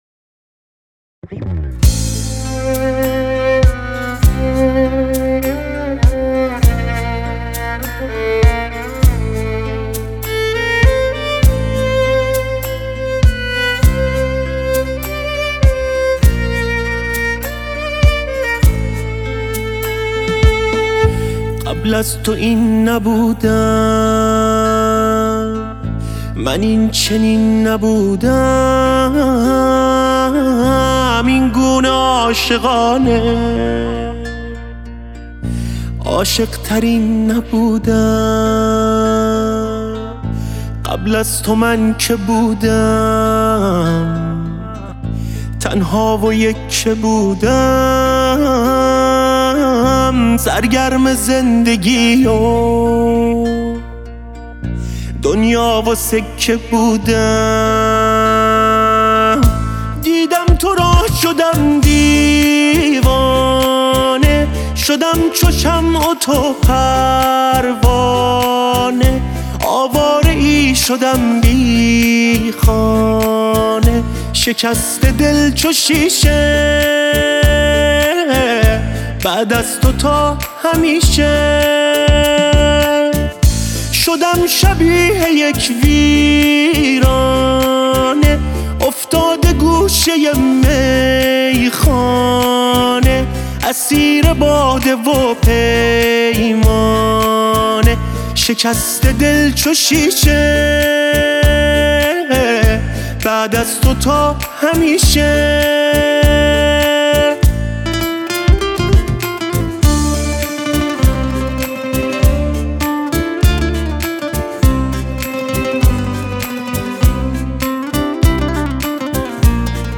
پاپ غمگین